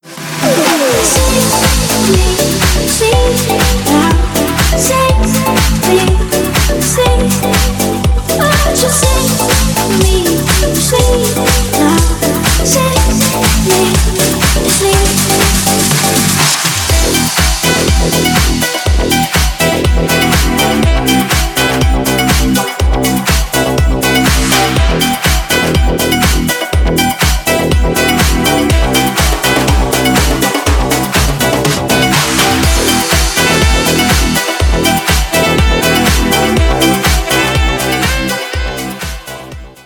• Качество: 320, Stereo
remix
dance